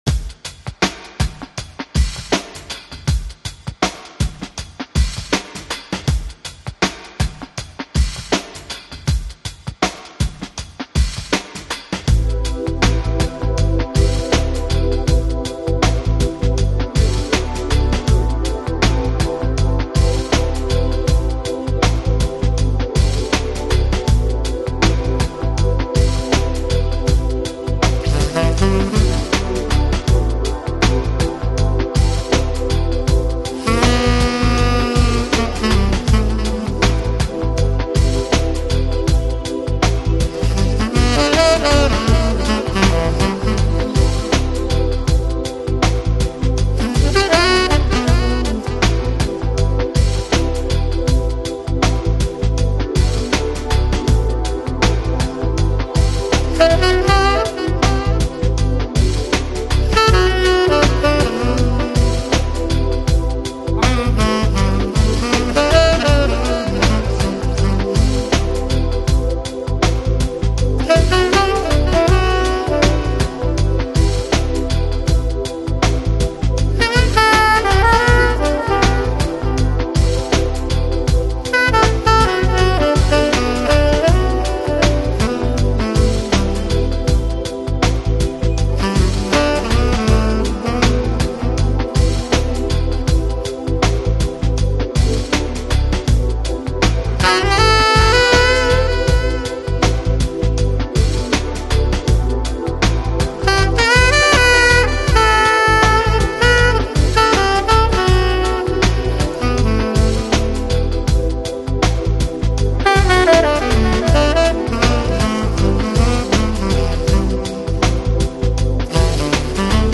Downtempo, Chill Out, Lounge